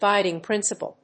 音節guìding prínciple